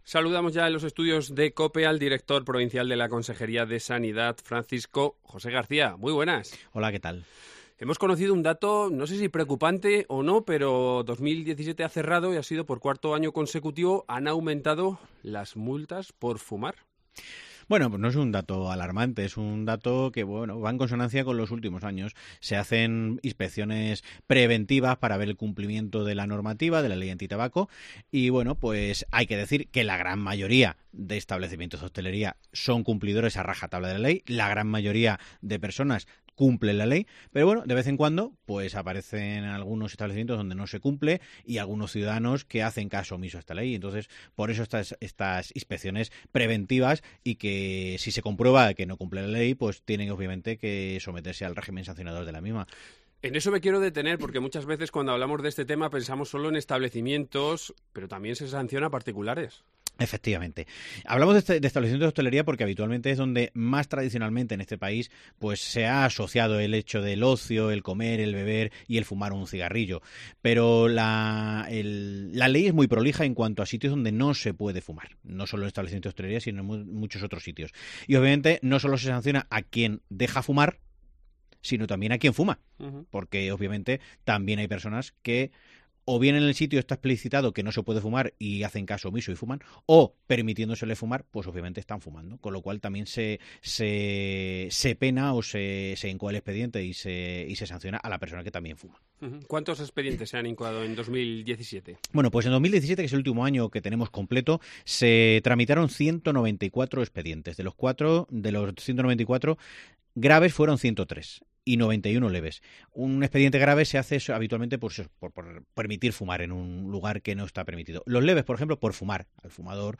Varios años después aún hoy hay locales y particulares que incumplen esta Ley, de hecho en 2017 se cerró el cuarto año consecutivo en el que se han incrementado las multas por fumar. Hoy hablamos con director provincial de Sanidad Francisco José García.